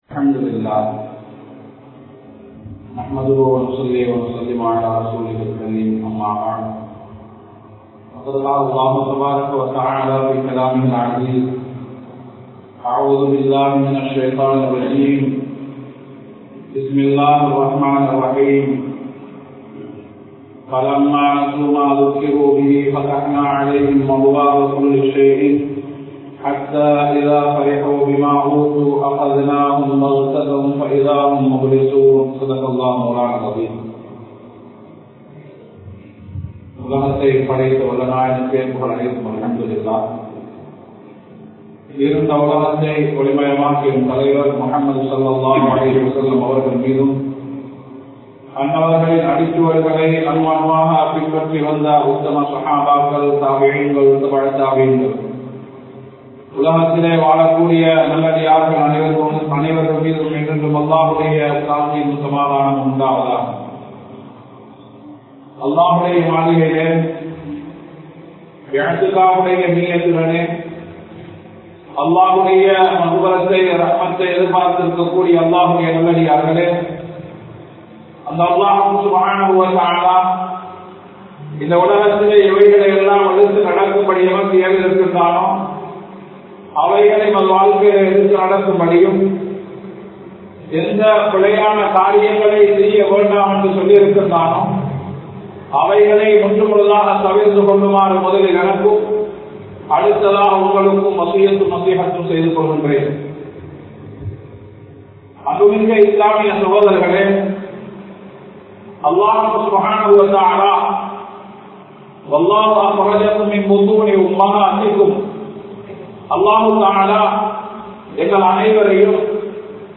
Manithanin Iruthi Mudivu (மனிதனின் இறுதி முடிவு) | Audio Bayans | All Ceylon Muslim Youth Community | Addalaichenai
Majmaulkareeb Jumuah Masjith